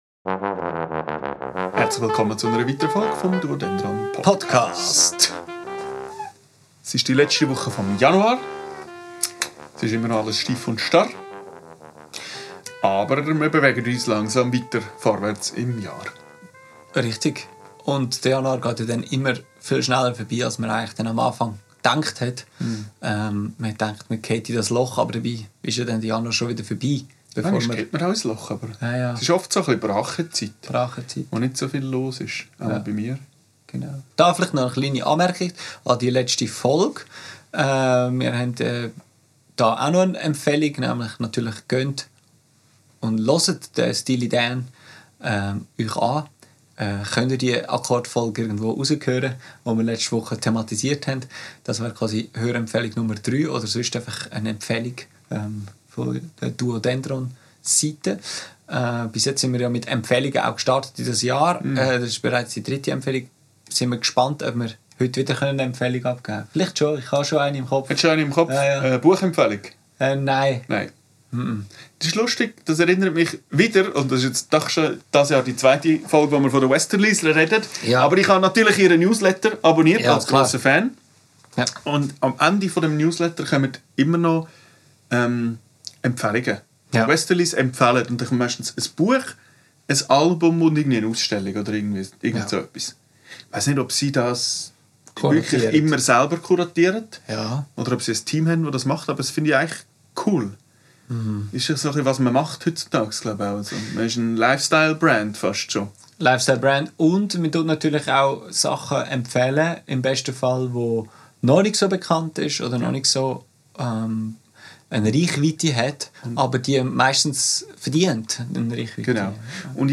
Aufgenommen am 30.12.2025 im Atelier